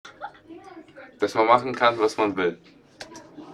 Standort der Erzählbox:
MS Wissenschaft @ Diverse Häfen